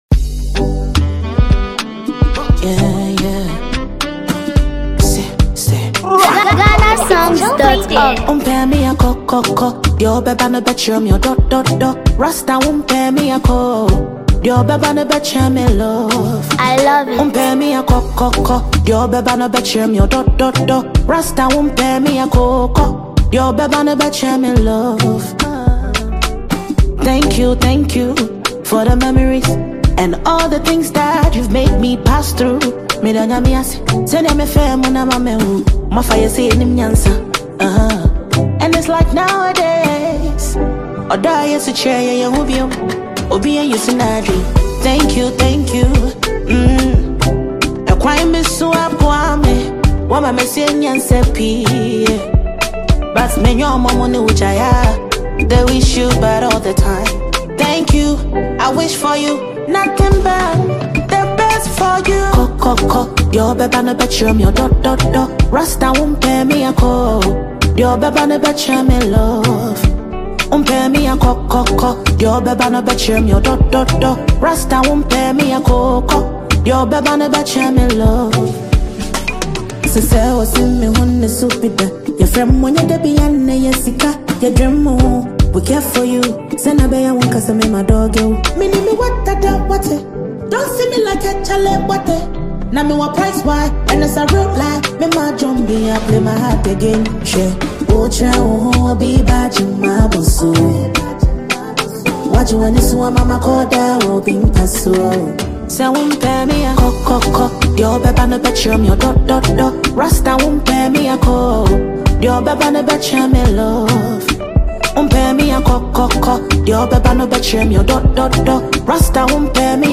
emotional vocals
soothing Afrobeat and highlife-inspired instrumental
soulful Afrobeat music